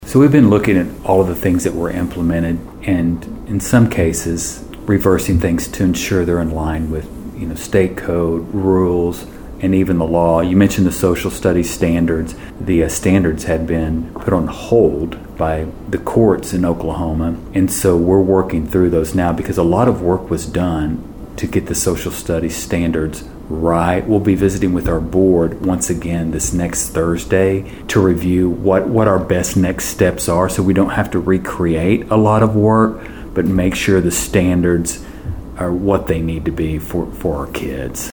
In a sit-down interview with Bartlesville Radio, Fields reflected on his first weeks in office and shared his vision for the future of Oklahoma education.